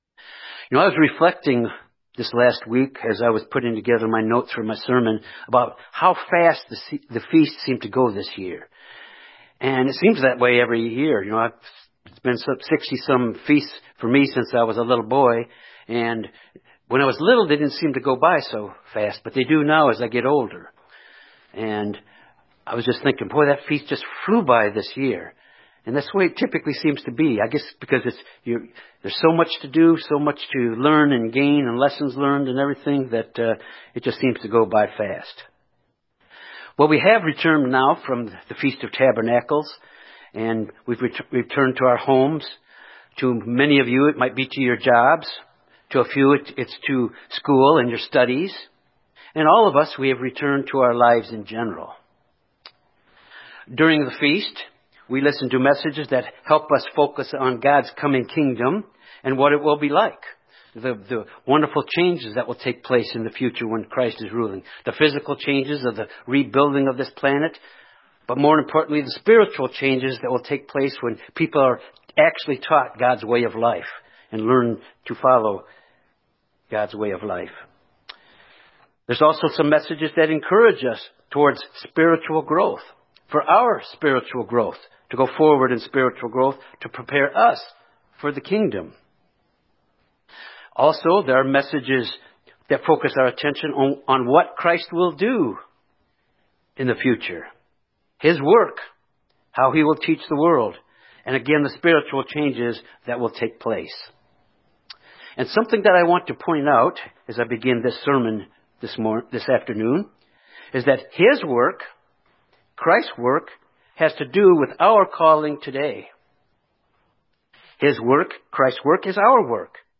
This sermon examines the importance of our examples to the world by living our lives according to the truth.